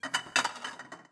Babushka / audio / sfx / Kitchen / SFX_Plates_01_Reverb.wav
SFX_Plates_01_Reverb.wav